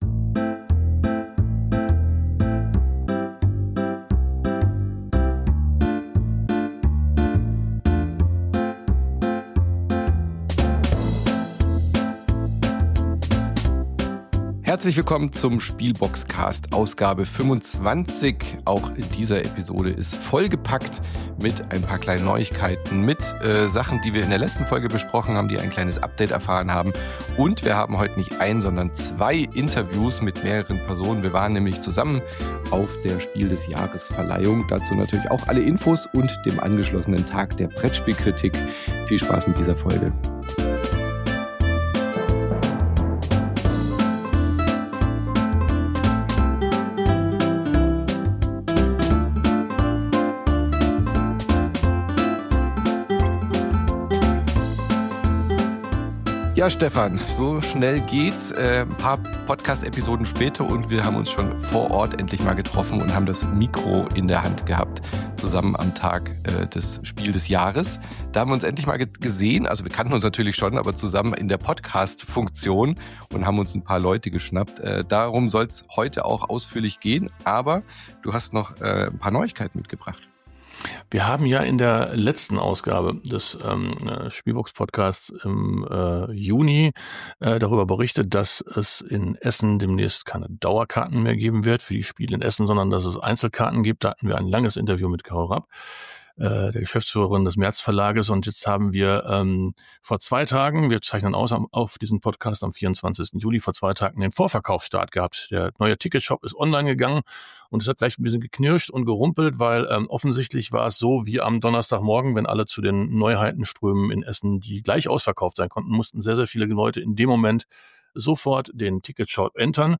Von Vorteil war dabei, dass man sich Mitte Juli 2025 gemeinsam bei der Preisverleihung der Jury Spiel des Jahres in Berlin getroffen hat, die natürlich auch Thema dieser Podcastfolge ist.